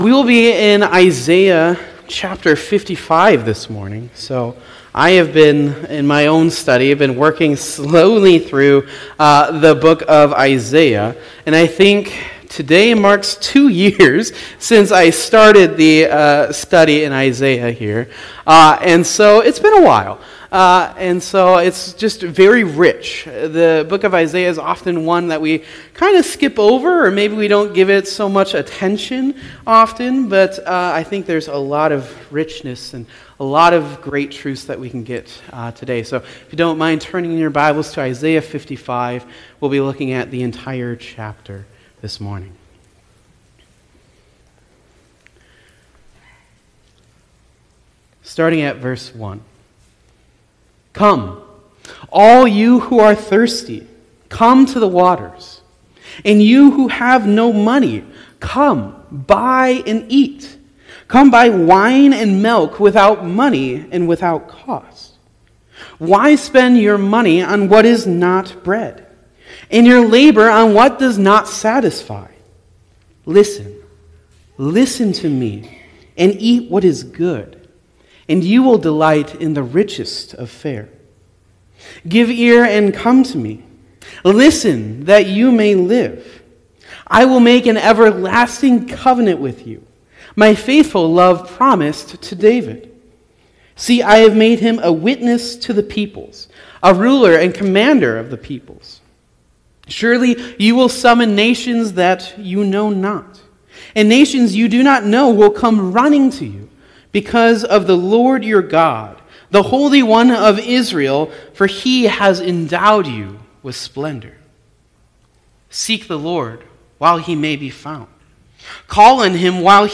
Sermon Recordings | Faith Community Christian Reformed Church
“The Invitation to Life” June 22 2025, A.M. Service